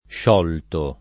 +š0lto] — qualche es. delle forme poet. contratte (modellate verso il ’400 sulle più antiche forme analoghe del v. togliere e sopravvissute in qualche modo fino all’800): Sciorrà questa catena un giorno morte [šorr# kkUeSta kat%na un J1rno m0rte] (Poliziano); e con i- prost.: Che vi posson legare, e non isciorre [k% vvi p0SSon leg#re, e nn1n išš0rre] (Burchiello); e con acc. scr.: sciòrre l’enigma de la Vita [š0rre l en&gma de lla v&ta] (D’Annunzio)